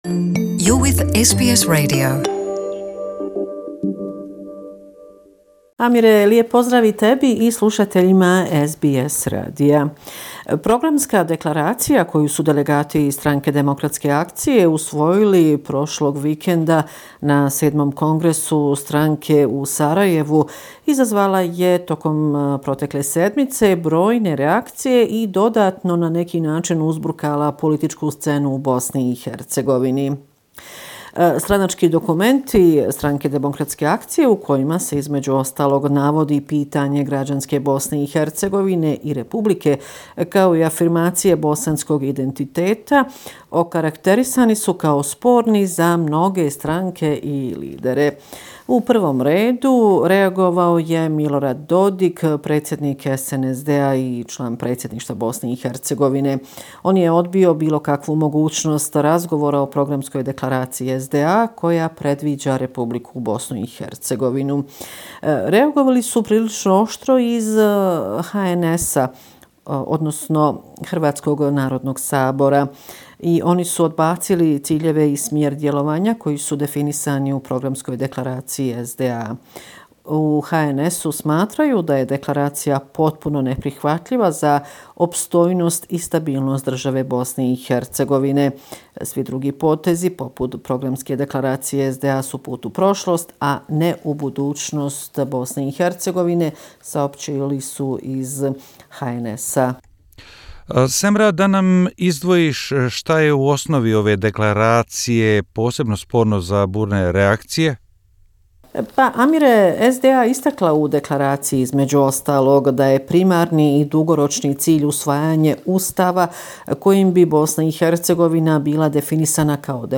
Bosnia and Herzegovina - affairs in the country for the last seven day, weekly report September 22, 2019